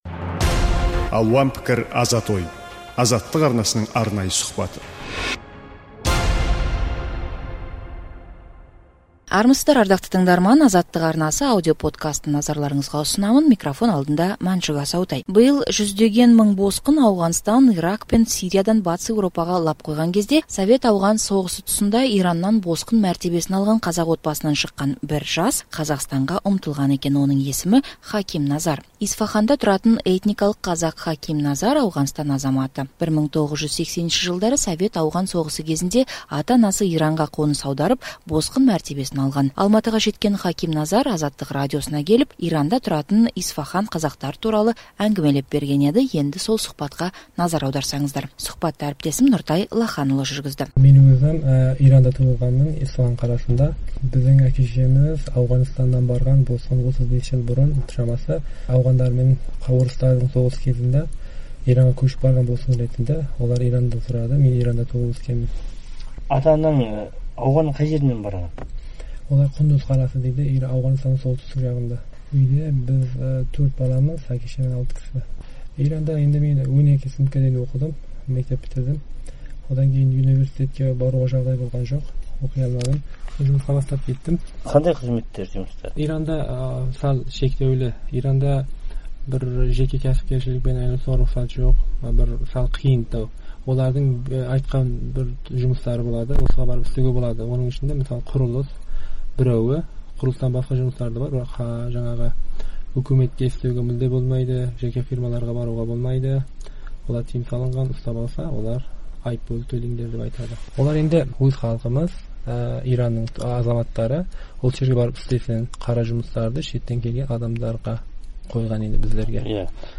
Ирандық босқын қазақпен сұхбат